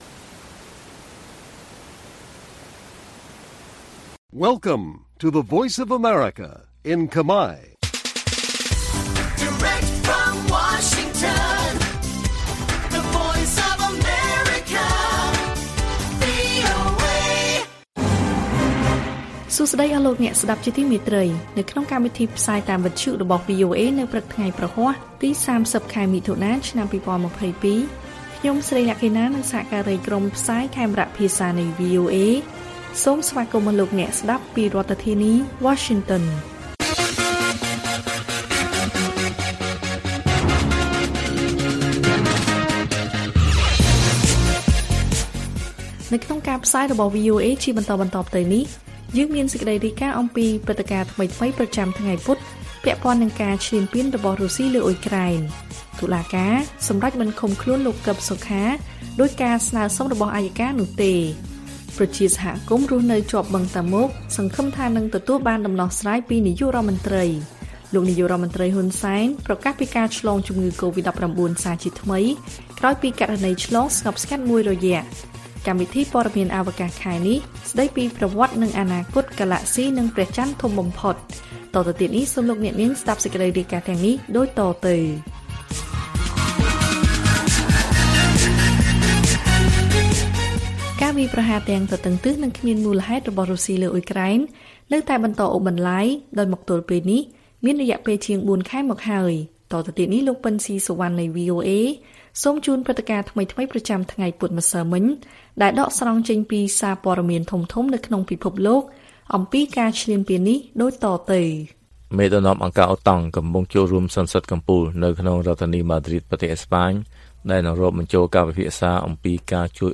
ព័ត៌មានពេលព្រឹក ៣០ មិថុនា៖ ព្រឹត្តិការណ៍ថ្មីៗប្រចាំថ្ងៃពុធពាក់ព័ន្ធនឹងការឈ្លានពានរបស់រុស្ស៊ីលើអ៊ុយក្រែន